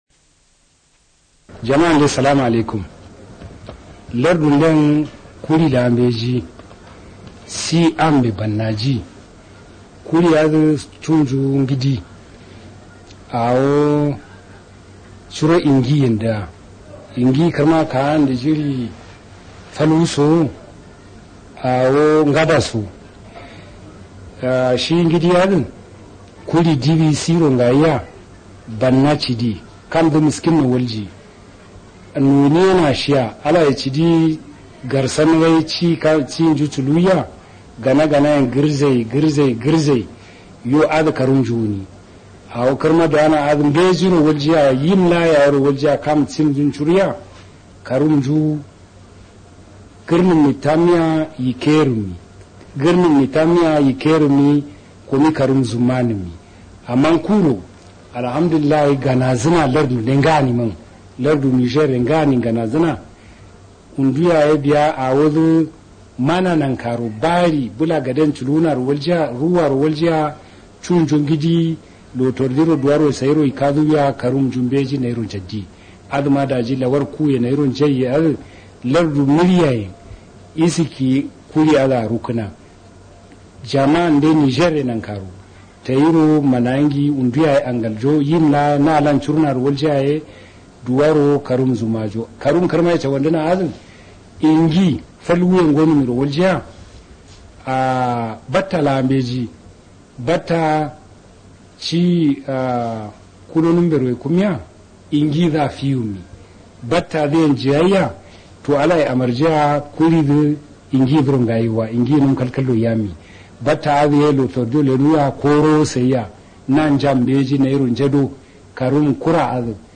Guinea Worm Public Service Announcements (PSAs)
Niger President Tandja Mamadou: